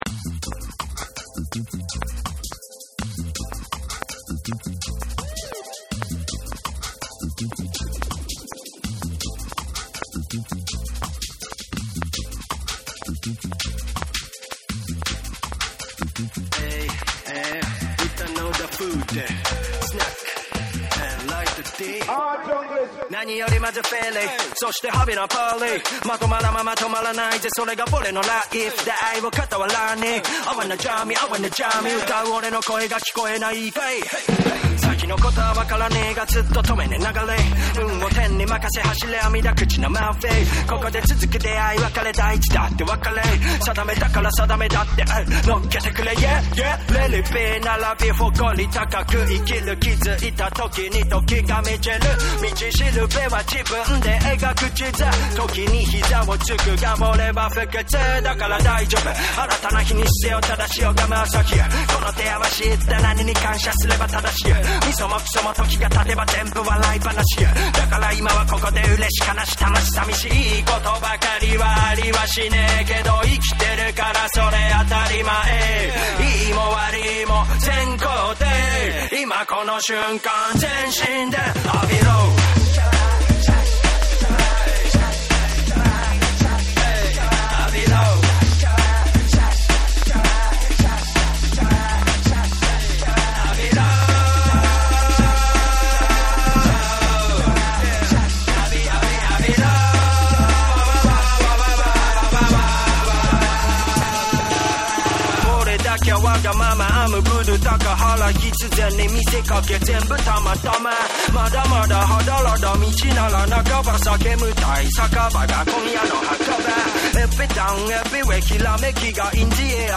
JAPANESE / REGGAE & DUB / NEW RELEASE(新譜)